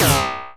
sci-fi_weapon_blaster_laser_fun_03.wav